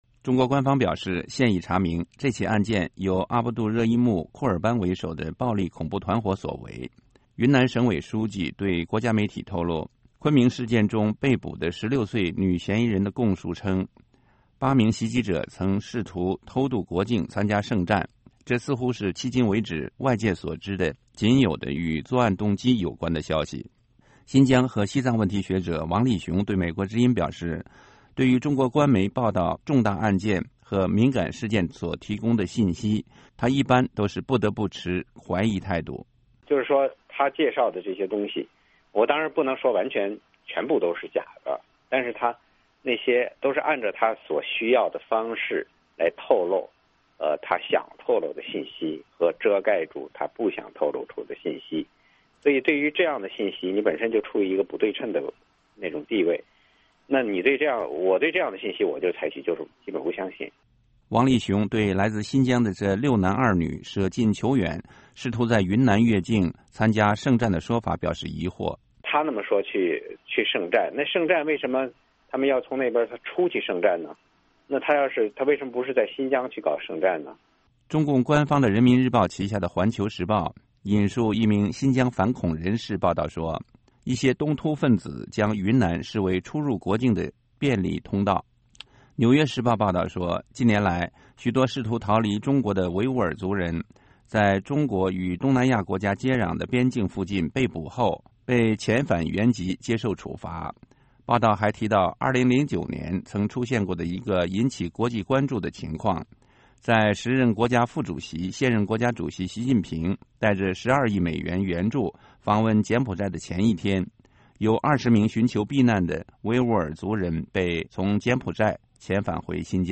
王力雄在接受美国之音电话采访时也提到了柬埔寨遣返新疆维族人的相关案例。
浦志强在接受美国之音电话采访时也就北京中央政府对昆明血案的反应和处理提出批评。